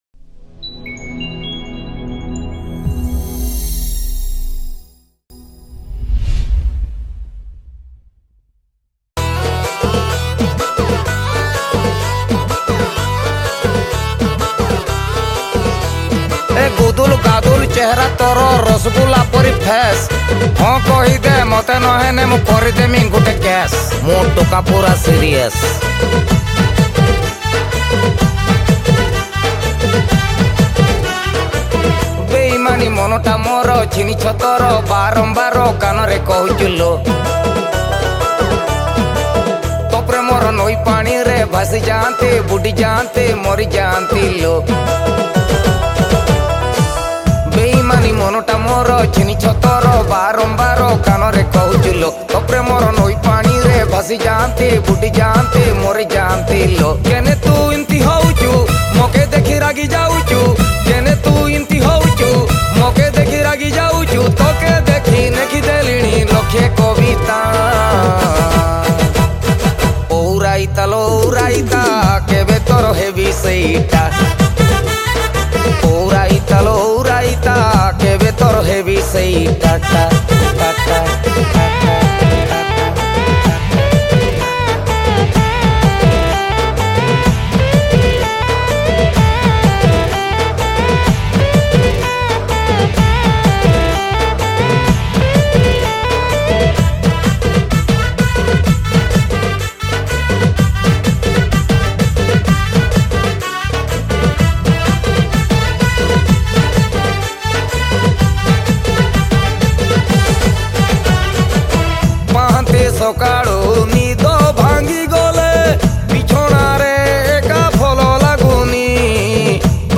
Arrangements & Keyboard